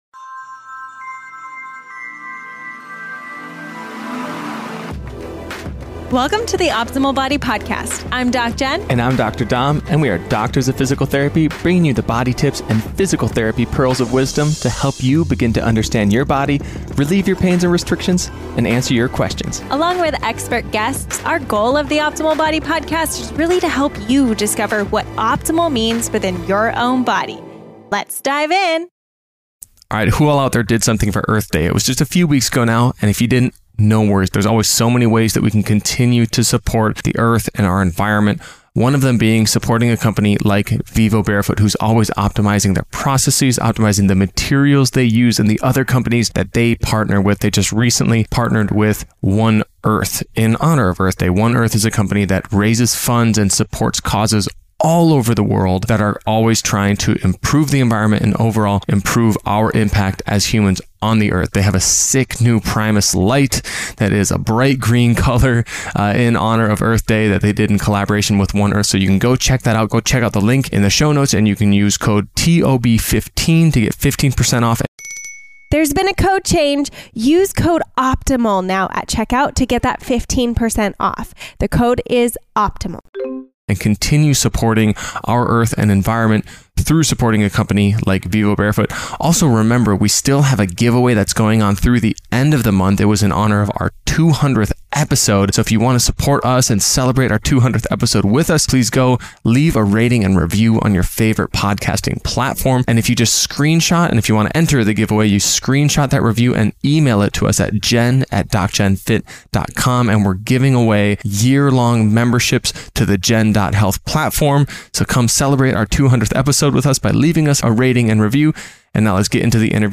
What You Will Learn in this interview